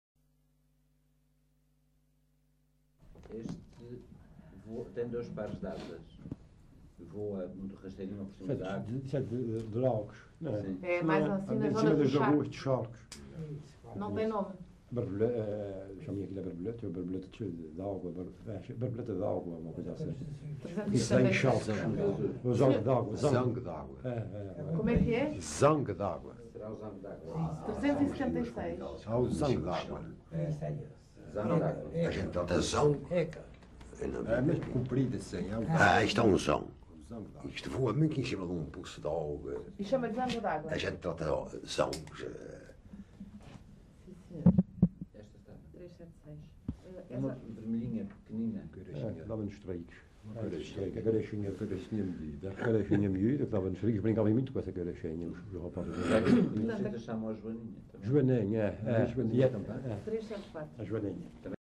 LocalidadePonta Garça (Vila Franca do Campo, Ponta Delgada)